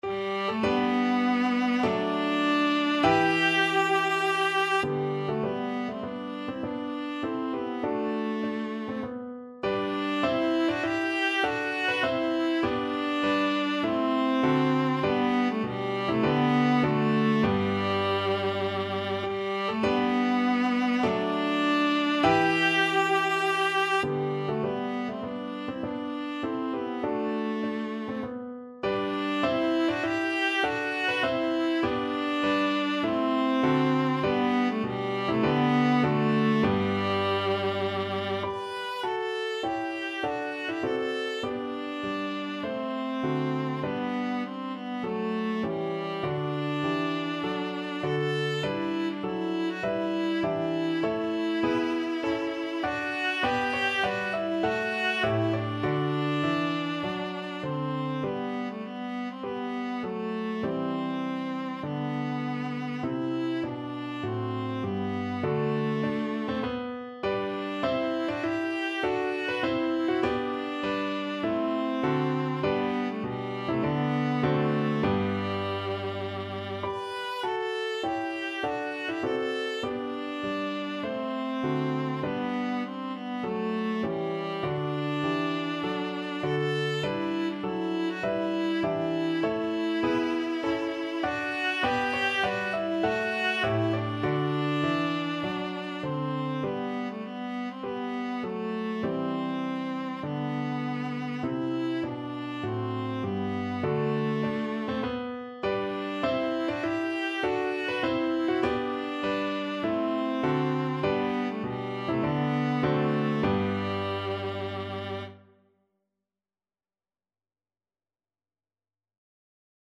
Viola
4/4 (View more 4/4 Music)
G major (Sounding Pitch) (View more G major Music for Viola )
Classical (View more Classical Viola Music)
handel_scipio_march_VLA.mp3